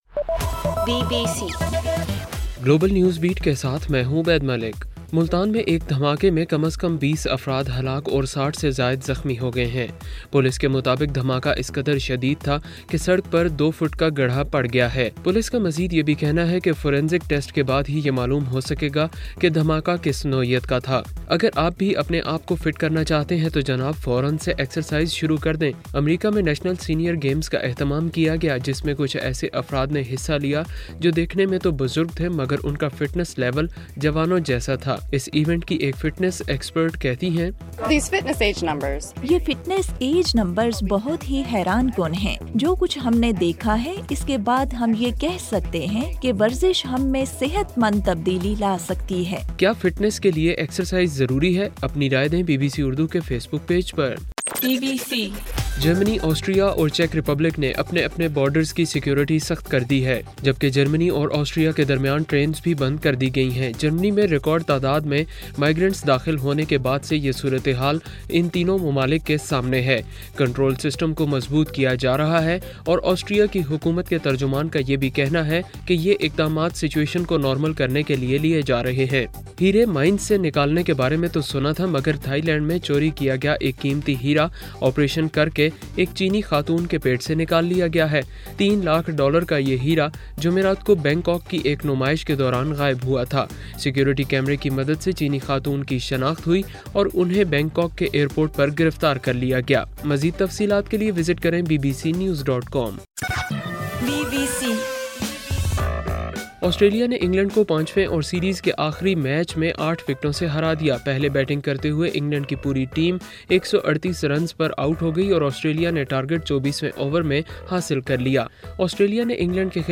ستمبر 14:صبح 1 بجے کا گلوبل نیوز بیٹ بُلیٹن